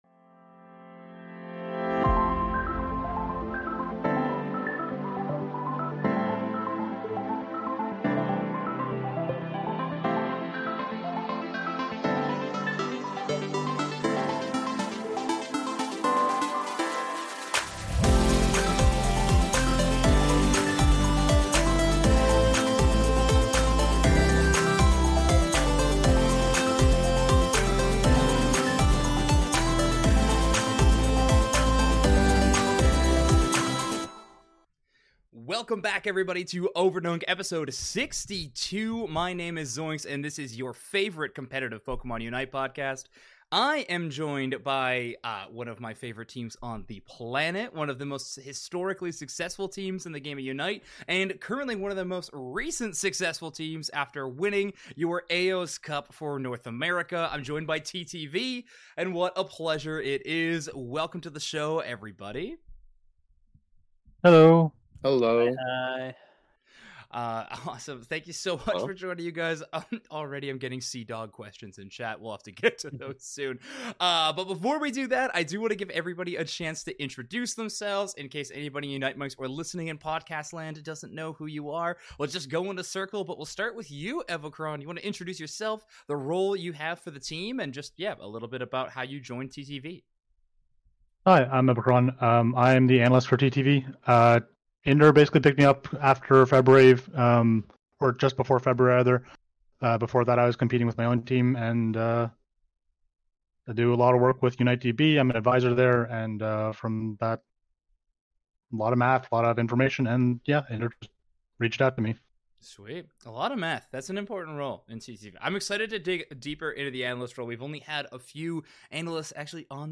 Overdunk Ep. 62 Interview with TTV!